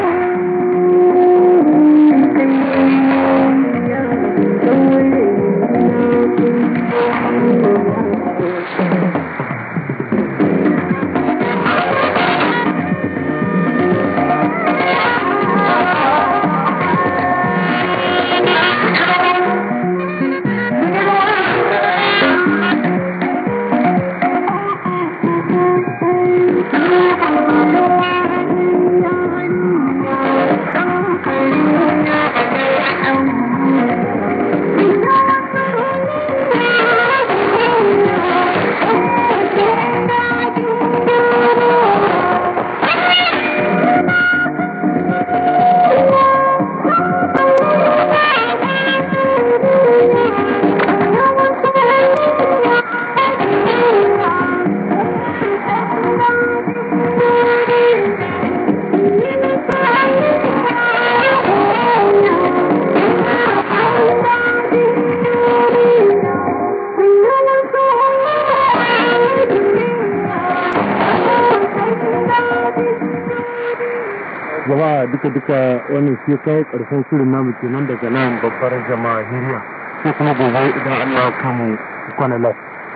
ID: identification announcement